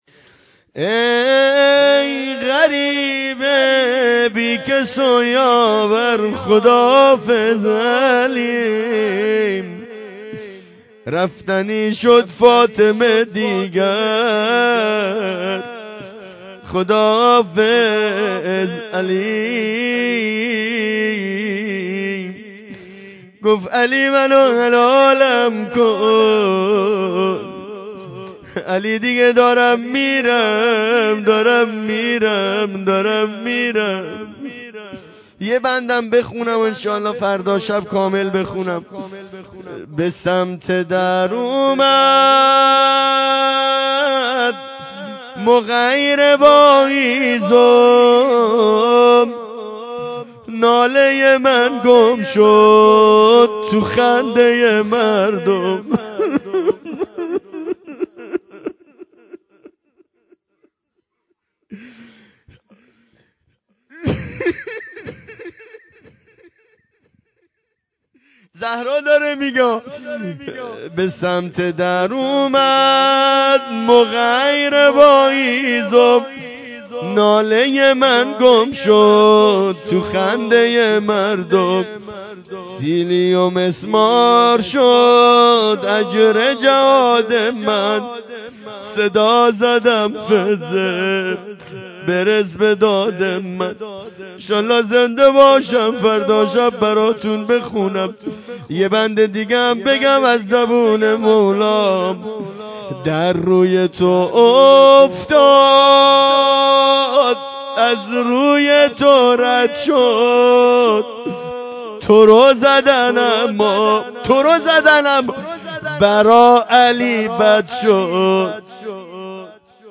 فاطمیه 1403 روایت 95 روز